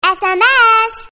забавные
милые
детский голос
голосовые